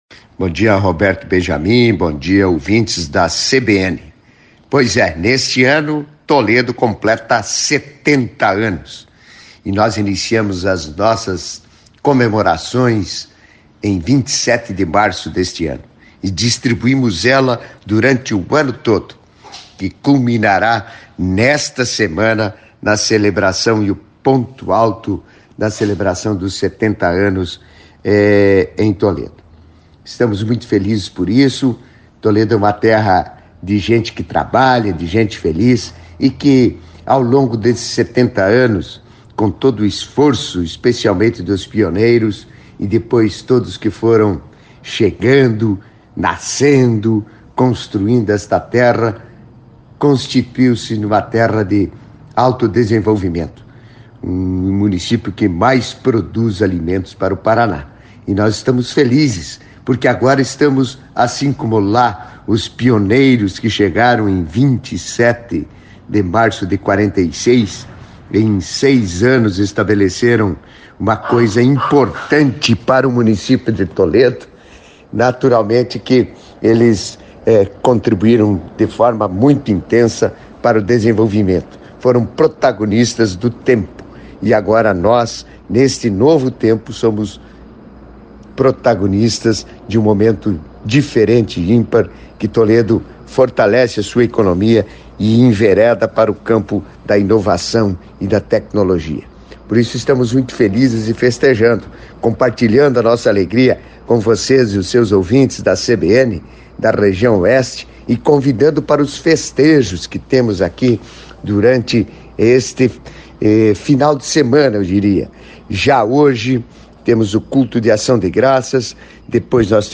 Em entrevista à CBN Cascavel nesta quinta-feira (15) Beto Lunitti, prefeito de Toledo, falou do aniversário do munícipio, que completou 70 anos no dia 14 de dezembro.